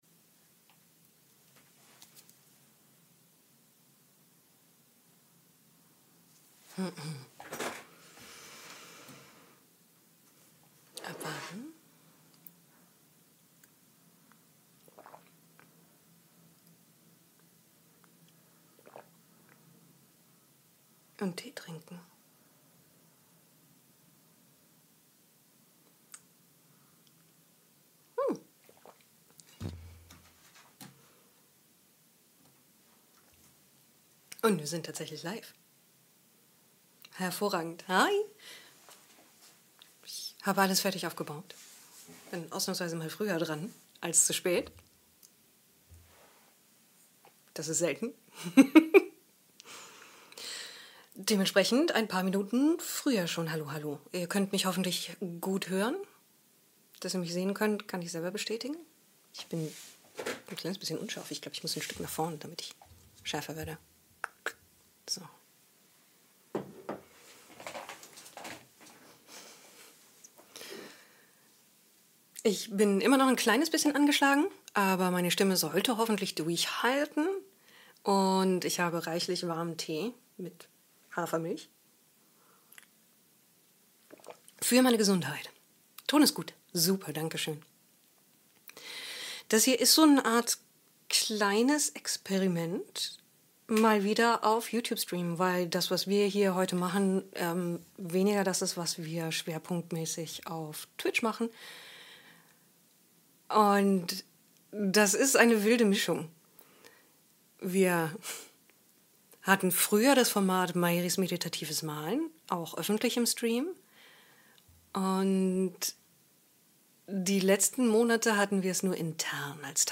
Dies ist der gekürzte und neu arrangierte Mitschnitt von 2 Livestreams, die wir im November 2024 in Lumley Castle im UK gemacht haben.